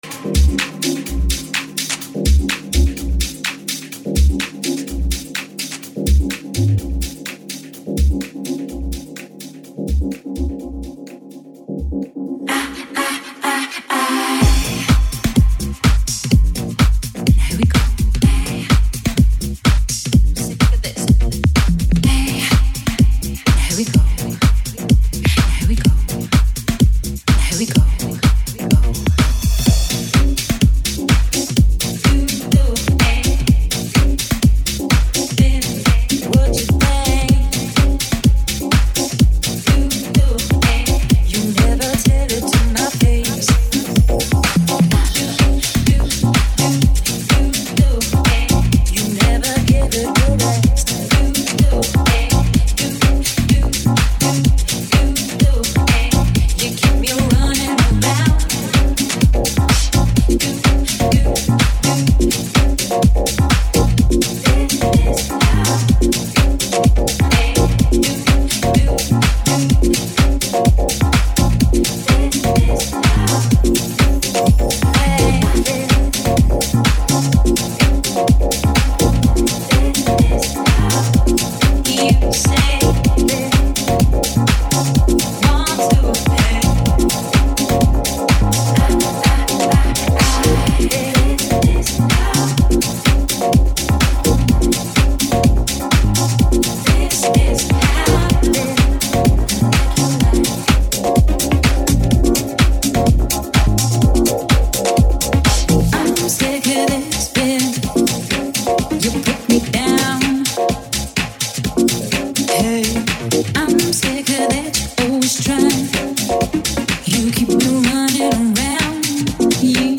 Electronic dance music re-makes or re-mixes